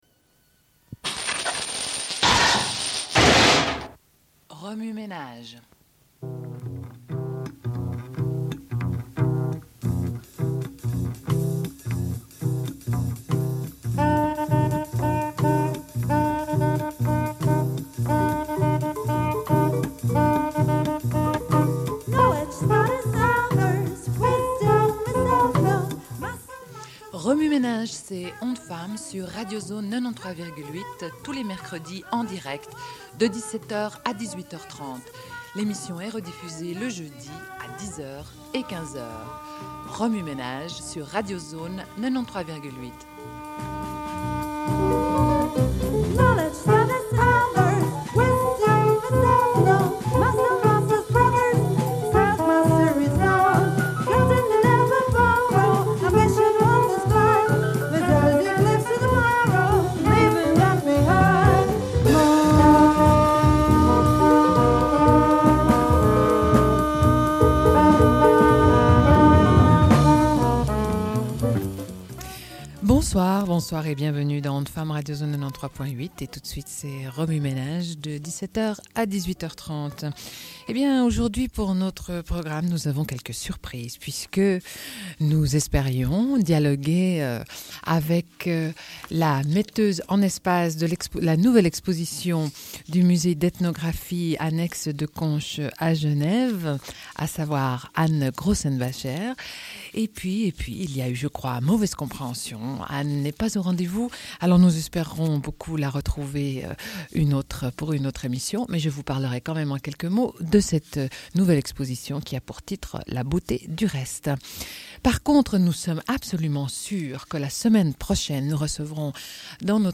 Une cassette audio, face A31:16
Lecture de deux portraits de femmes.